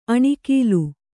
♪ aṇikilu